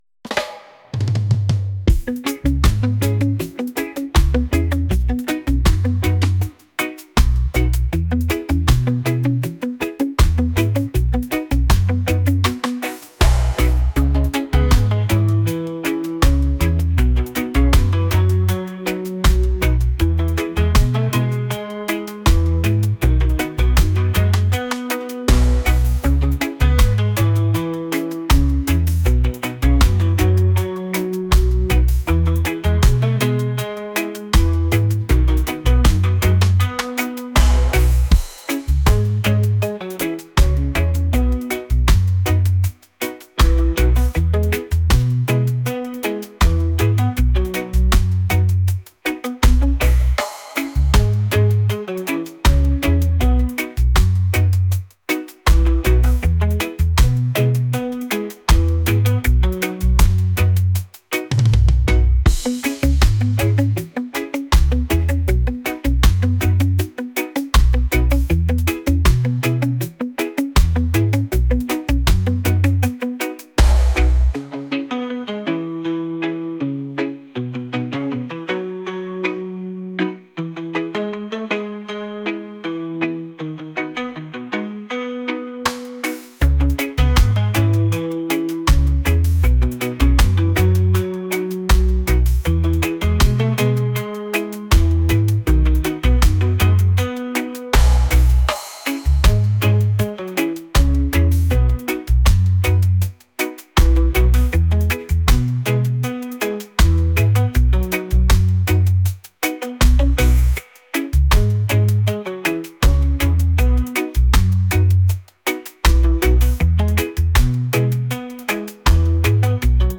reggae | lofi & chill beats | funk